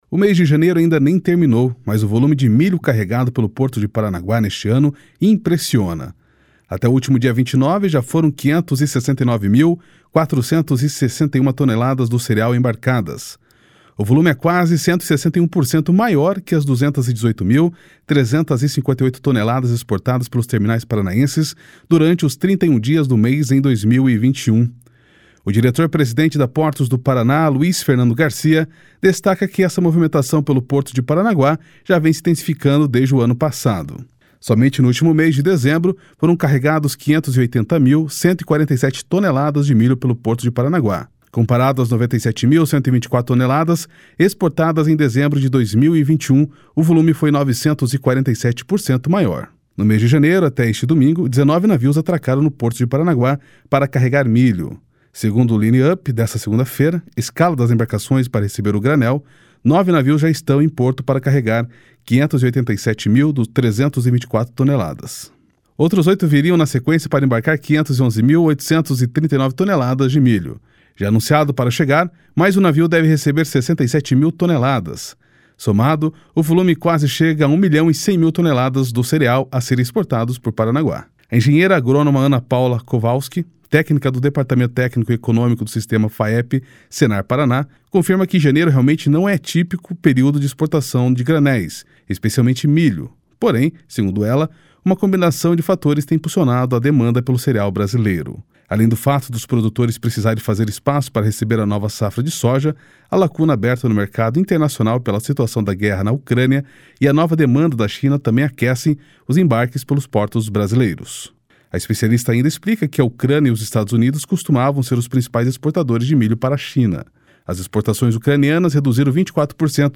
O diretor-presidente da Portos do Paraná, Luiz Fernando Garcia, destaca que essa movimentação pelo Porto de Paranaguá já vem se intensificando desde o ano passado.